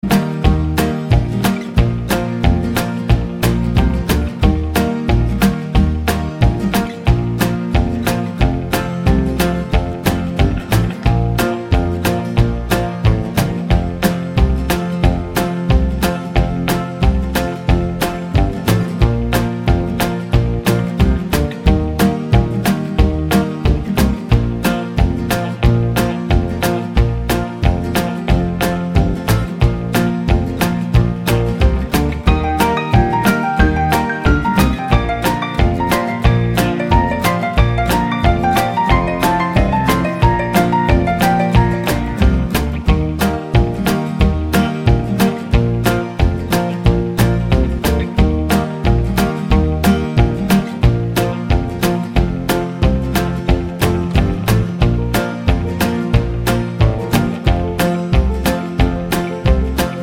no Backing Vocals Country (Male) 2:15 Buy £1.50